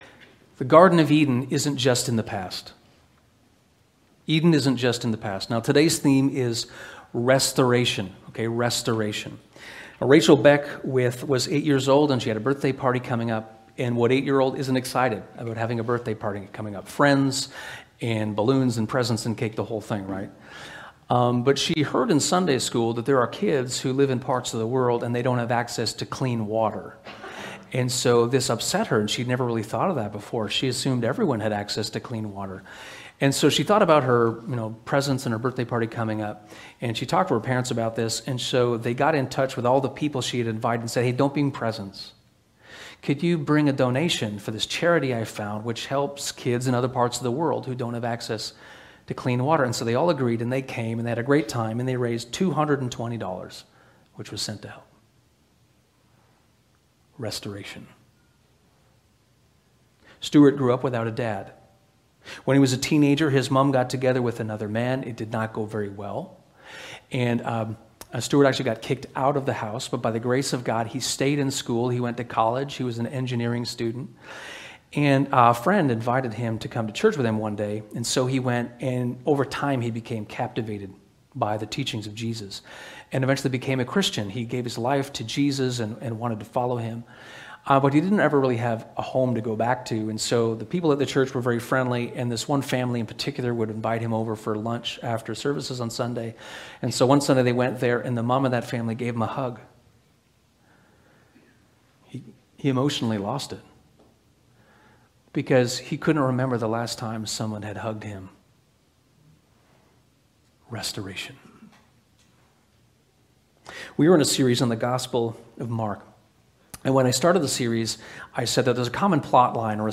This sermon takes us through Mark 1:40 - 2:17 and is a part of a series on Mark. We explore the text, consider how Jesus' actions fit into the larger story of God's work in the world, and ponder our own role as imitators of the great King of restoration.